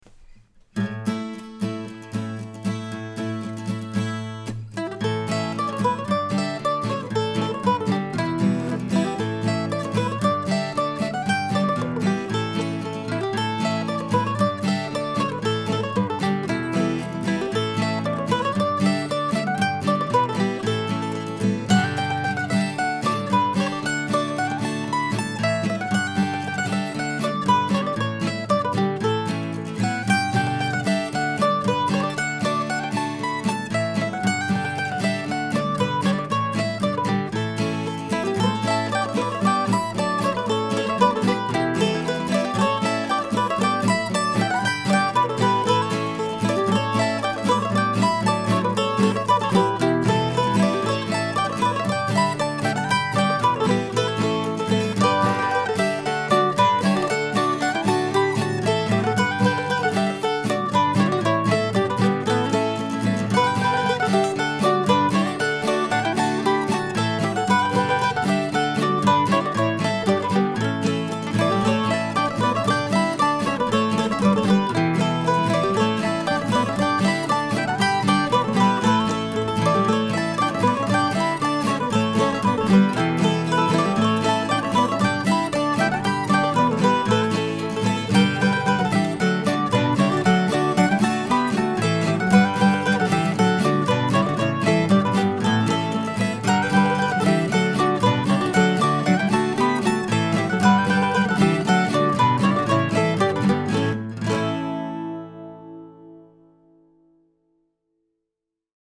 The recording presented here, however, is just me playing guitar and mandolin, recorded on Friday last week.